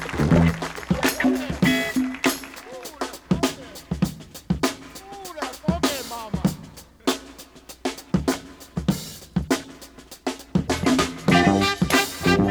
Ok so here is my break.
live at the PJs